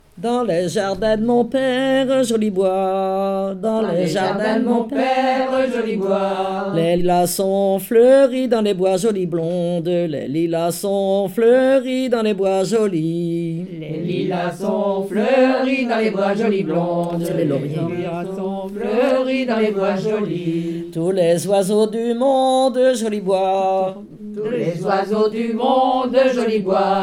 chansons traditionnelles
Pièce musicale inédite